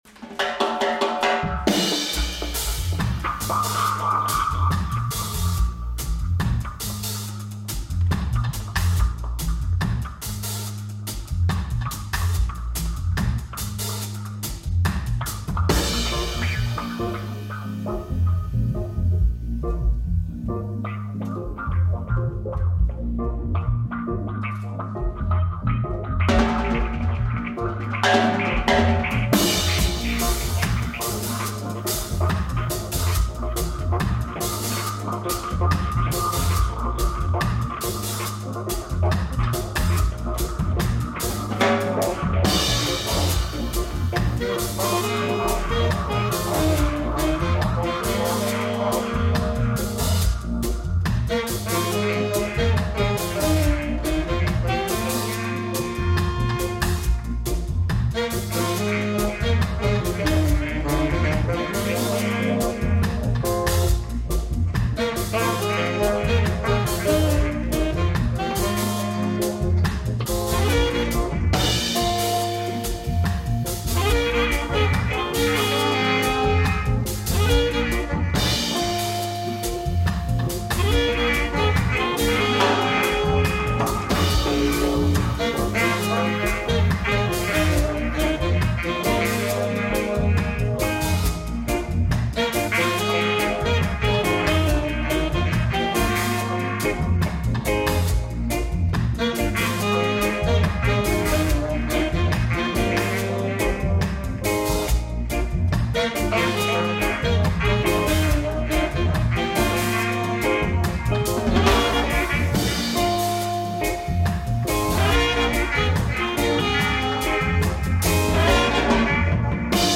I learned the art of live dub mixing.